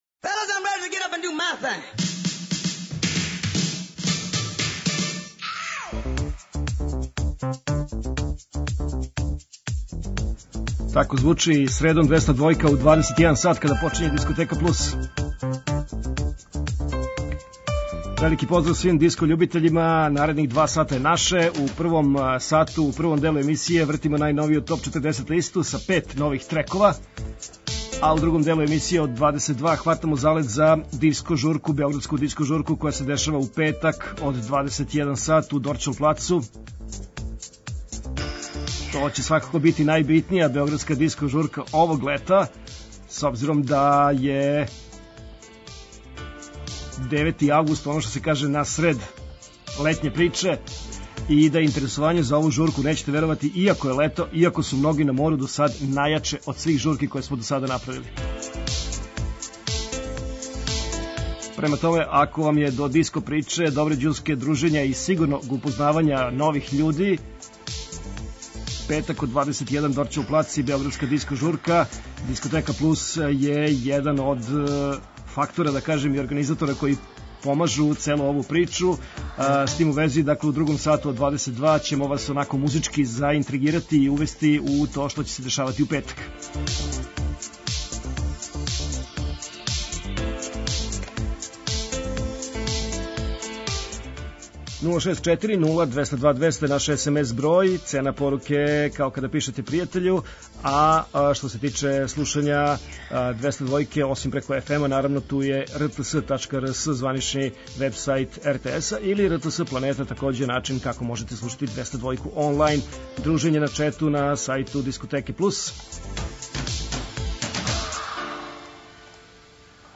Eмисија посвећена најновијој и оригиналној диско музици у широком смислу.
Заступљени су сви стилски утицаји других музичких праваца - фанк, соул, РнБ, итало-диско, денс, поп. Сваке среде се представља најновија, актуелна, Топ 40 листа уз непосредан контакт са слушаоцима и пуно позитивне енергије.